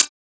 kits/OZ/Closed Hats/Hihat 1.wav at main
Hihat 1.wav